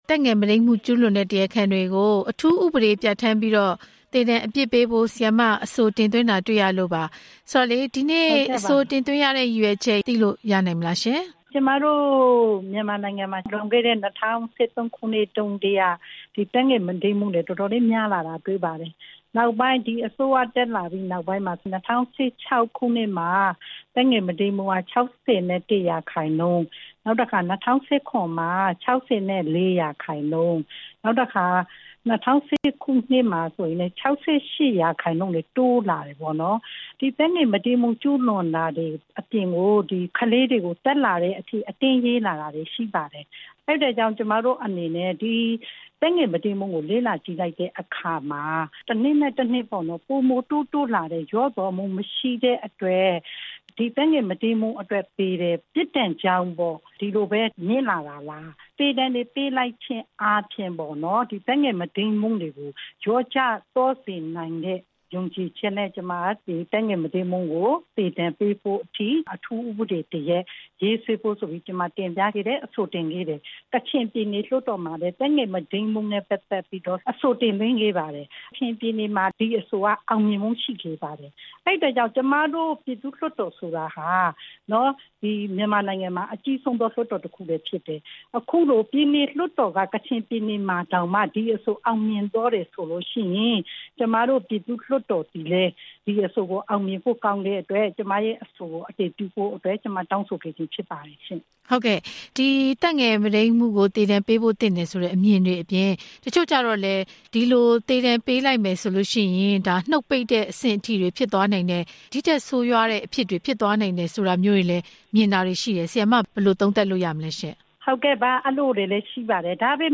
သက်ငယ်မုဒိမ်းမှု ပြစ်ဒဏ်ချမှတ်ရေးအဆိုတင်သူ ဒေါ်ခင်စောဝေနဲ့ ဆက်သွယ်မေးမြန်းချက်